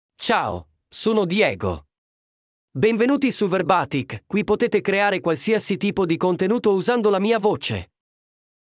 MaleItalian (Italy)
DiegoMale Italian AI voice
Diego is a male AI voice for Italian (Italy).
Voice sample
Listen to Diego's male Italian voice.